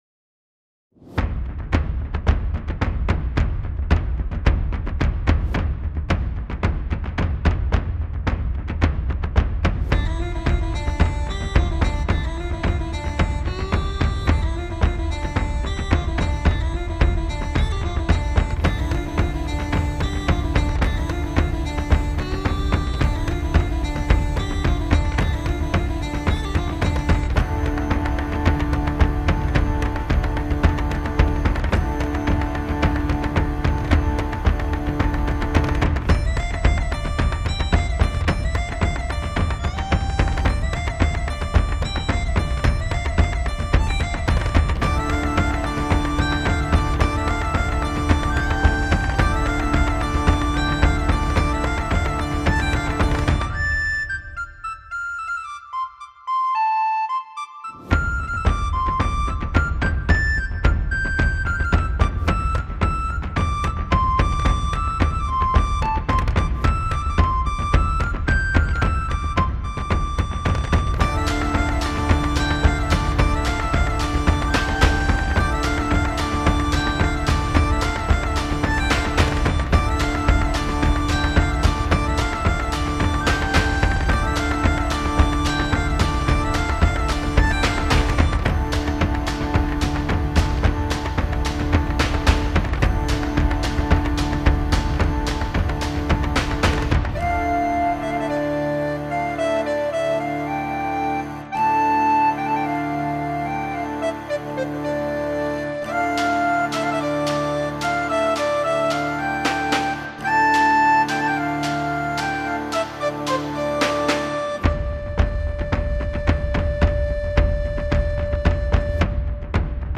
Celtic-Music-Warriors-Dance-1.mp3